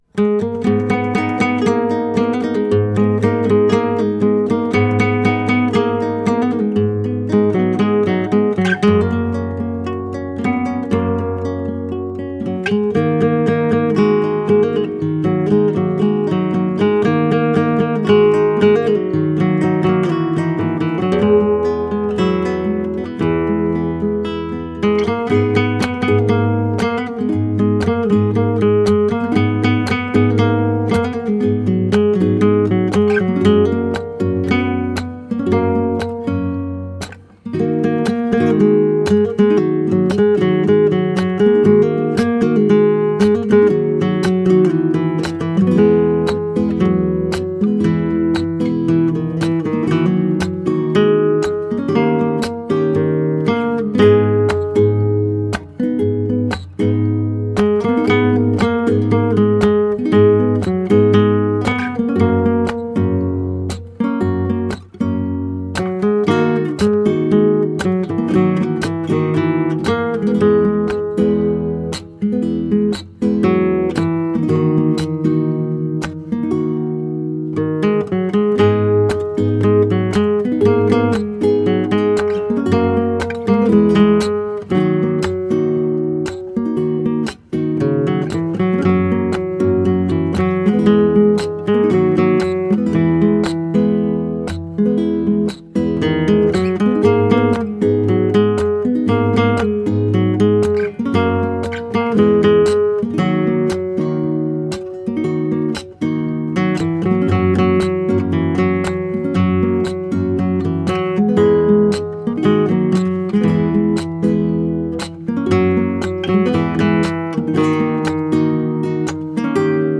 گام قطعهG#m
متر قطعه2/4
این قطعه در متر دو چهار و در گام  G#m نگارش شده.